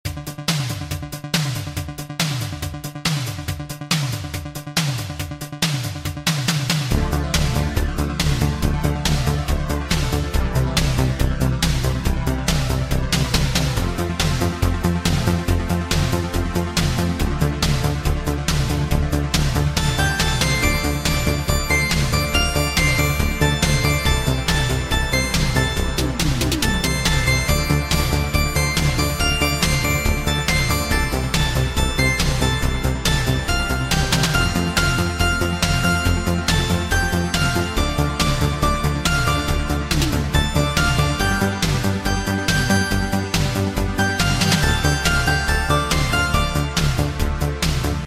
Cheesy 80s Italo/HI-NRG track140 BPM